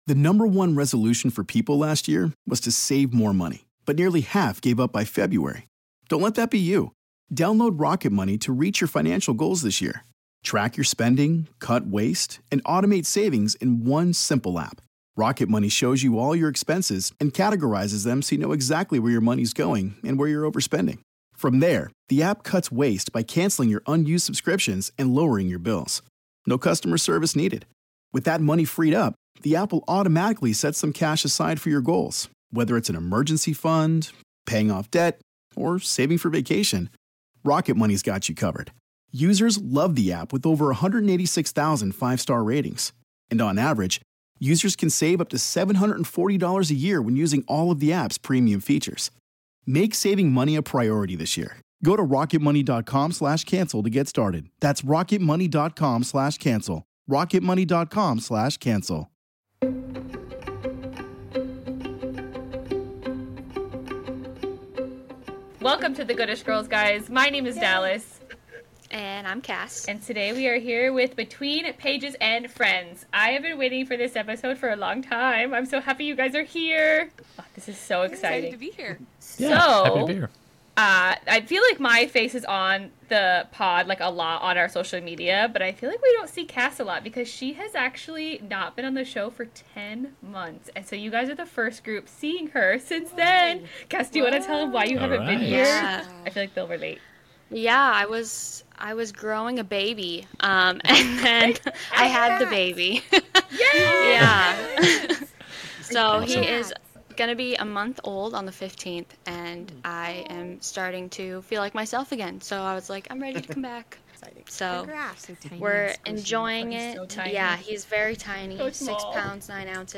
The Good-ish Girls and Between Pages and Friends talk Quicksilver and Brimstone theories, lore and more !!!